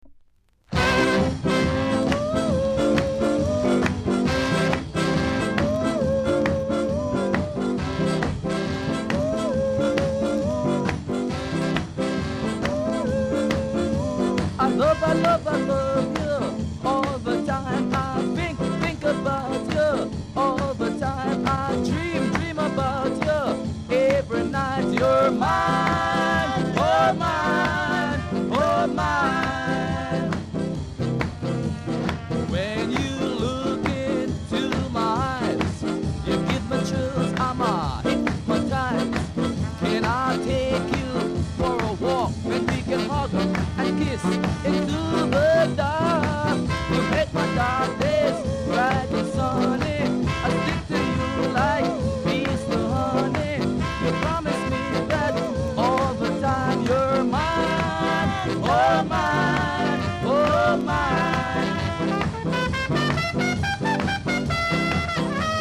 SKA CLASSIC!!